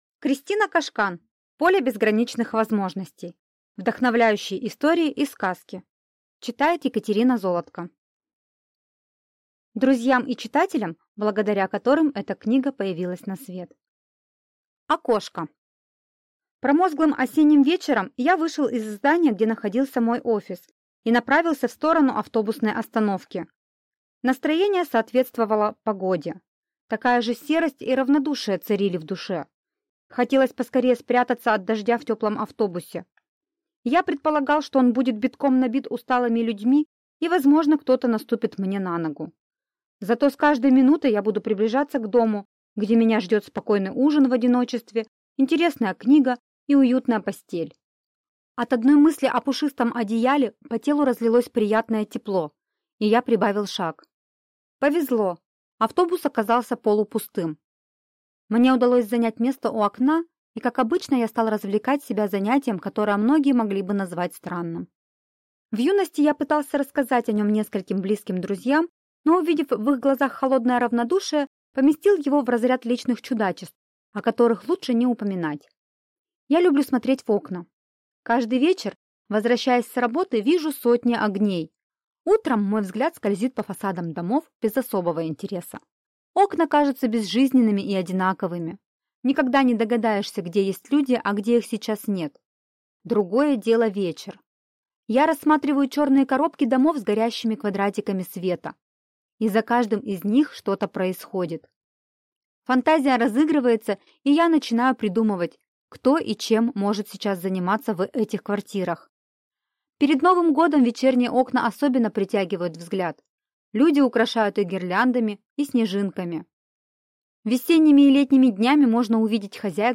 Аудиокнига Поле Безграничных Возможностей. Вдохновляющие истории и сказки | Библиотека аудиокниг